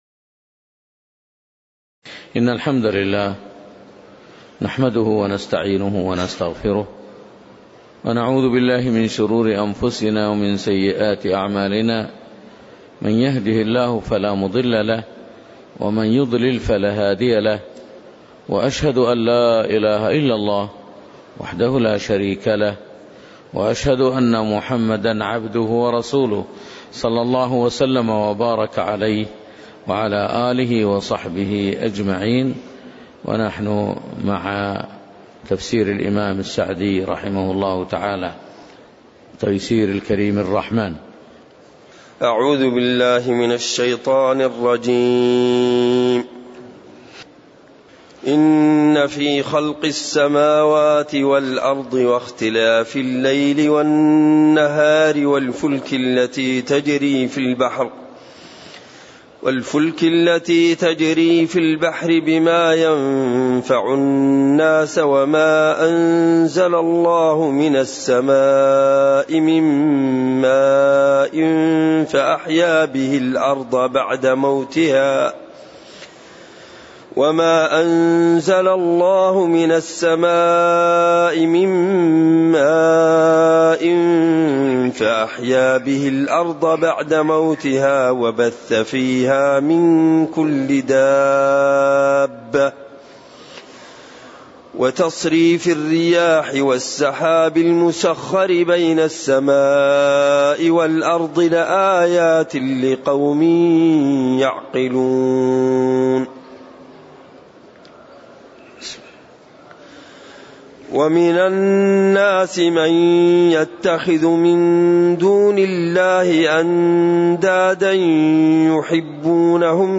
تاريخ النشر ٣ شعبان ١٤٣٨ هـ المكان: المسجد النبوي الشيخ